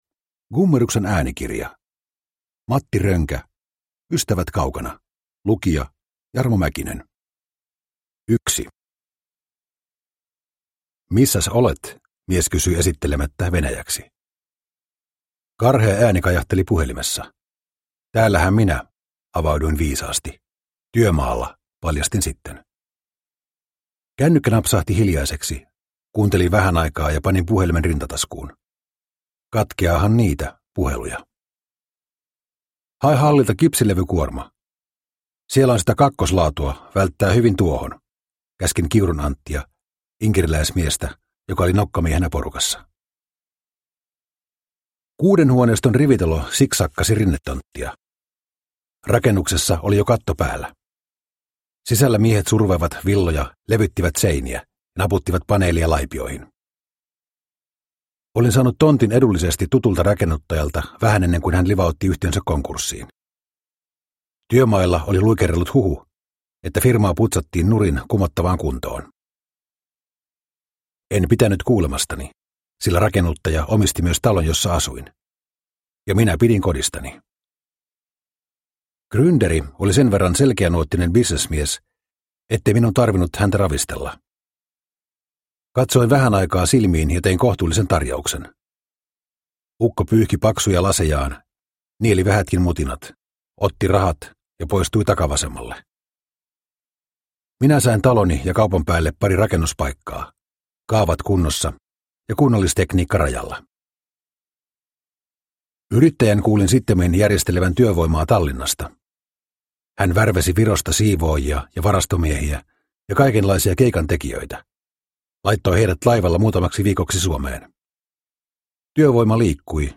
Ystävät kaukana – Ljudbok